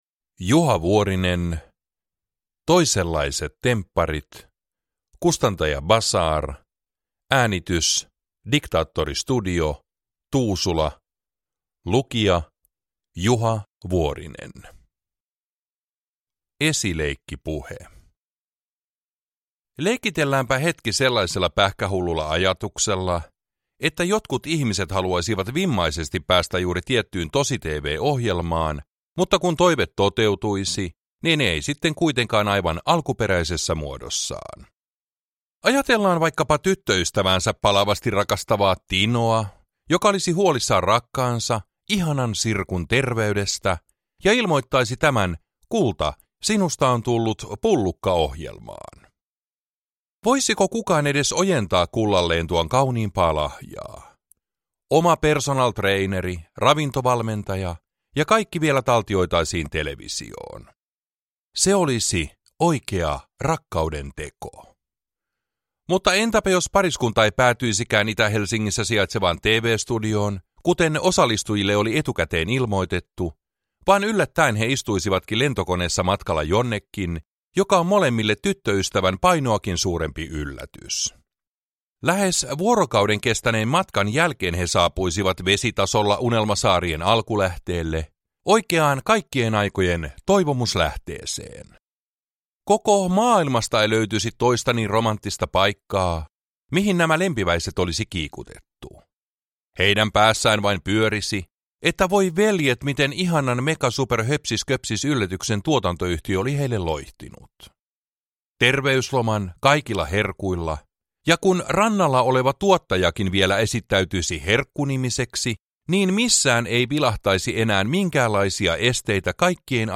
Toisenlaiset Tempparit – Ljudbok
Uppläsare: Juha Vuorinen